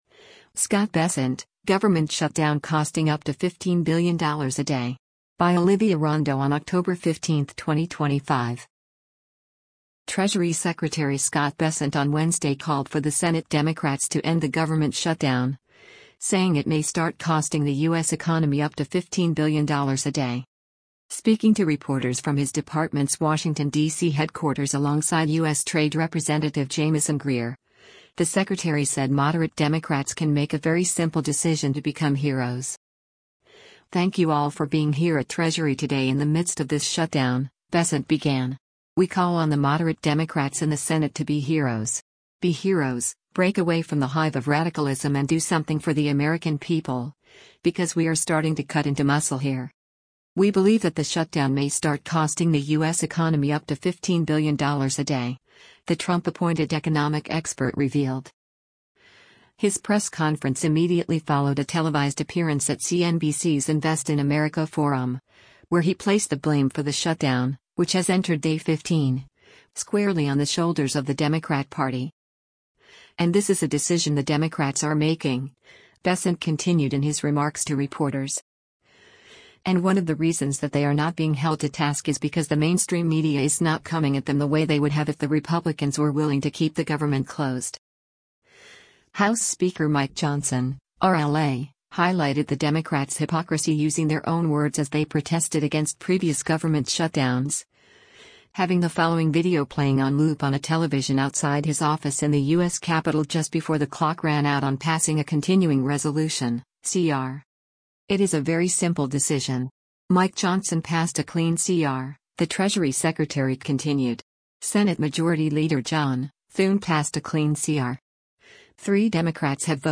Speaking to reporters from his department’s Washington, D.C. headquarters alongside U.S. Trade Representative Jamieson Greer, the secretary said “moderate Democrats” can make a “very simple decision” to become “heroes.”